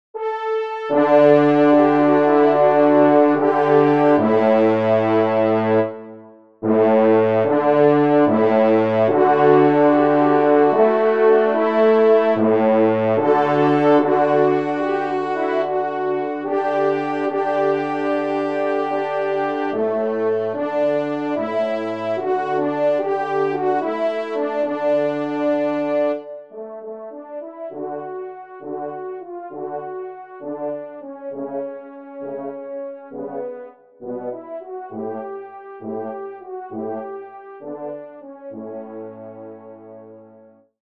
Genre : Divertissement pour Trompes ou Cors
Pupitre 5° Cor